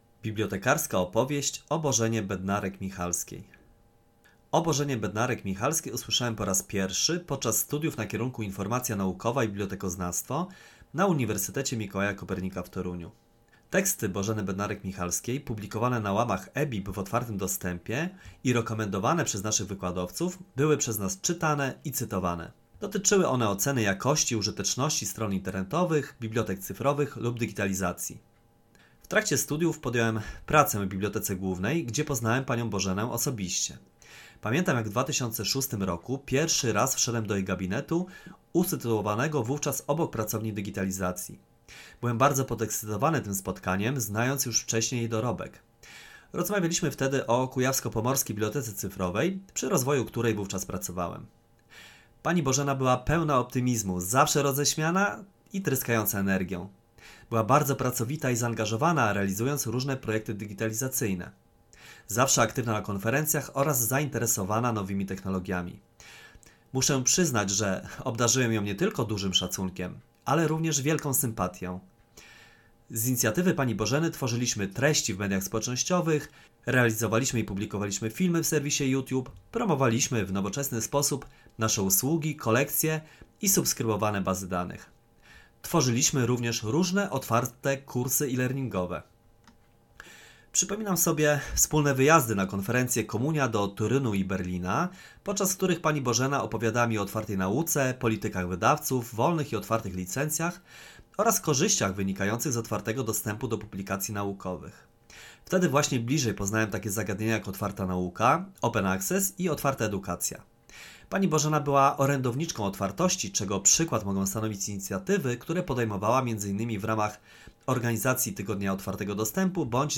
Historia mówiona